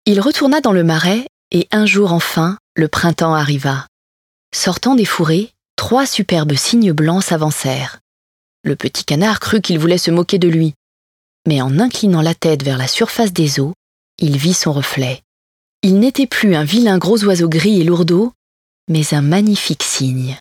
Livre audio : Le vilain petit canard
Voix de la narratrice